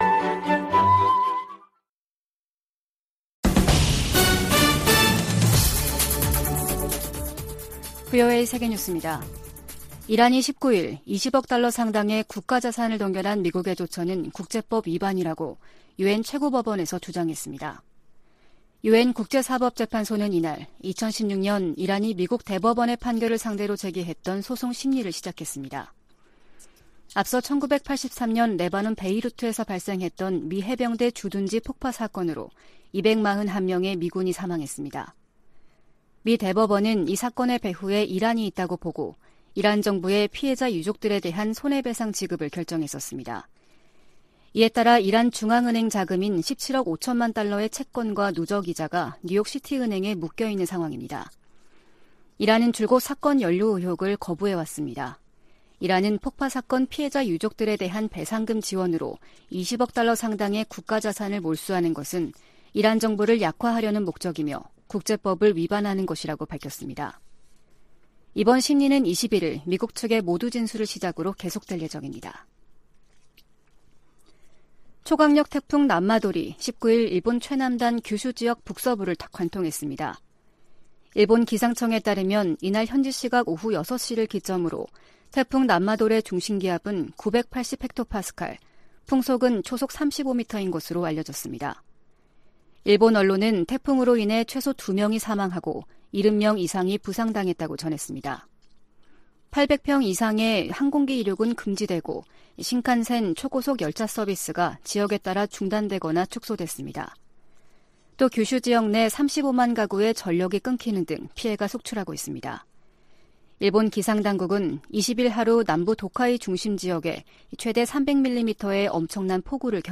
VOA 한국어 아침 뉴스 프로그램 '워싱턴 뉴스 광장' 2022년 9월 20일 방송입니다. 미국은 대북 억제를 위해 전략자산의 효과적인 역내 전개와 운용이 지속되도록 한국과의 공조 강화를 약속했습니다. 미 해군은 로널드 레이건 항공모함이 부산에 입항해 한국군과 연합훈련할 계획이라고 밝혔습니다. 제77차 유엔총회에서 미국은 식량 안보와 보건 협력, 안보리 개혁 문제를 주요 우선순위로 다룹니다.